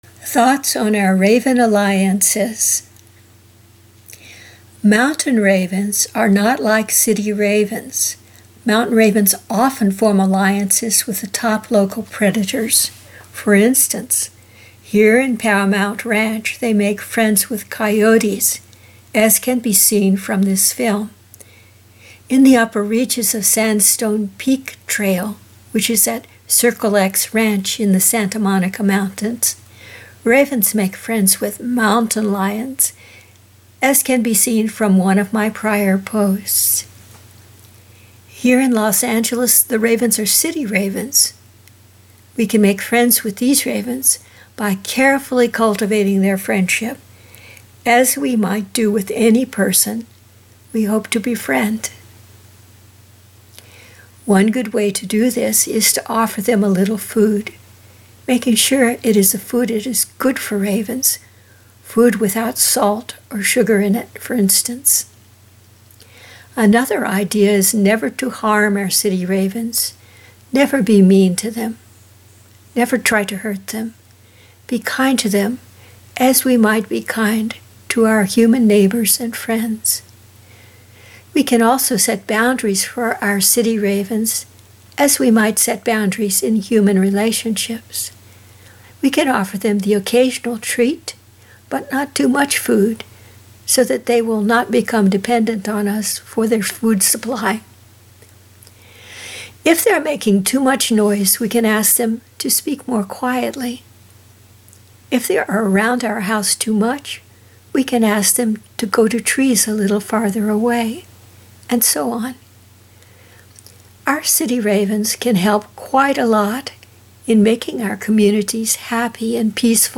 I was talking with the Coyotes and the Hummingbird had some lively comments meanwhile.
Location: Paramount Ranch, Santa Monica Mountains, CA